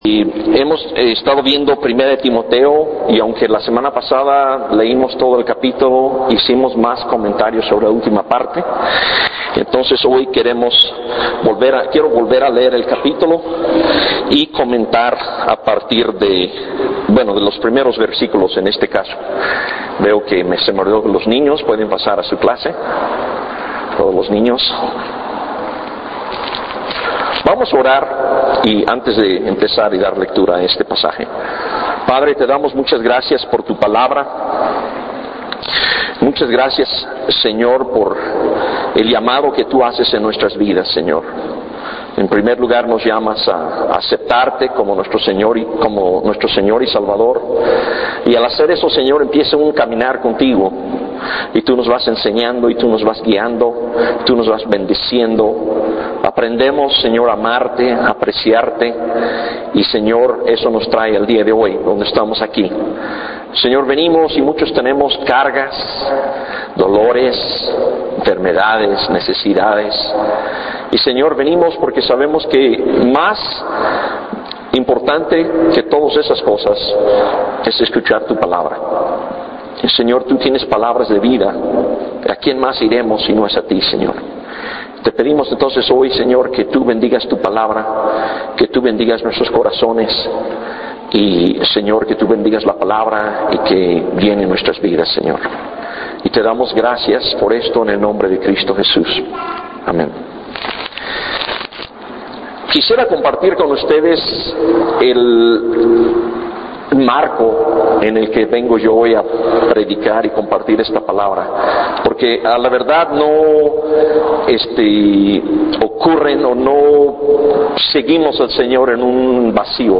Sermones de 1 Timoteo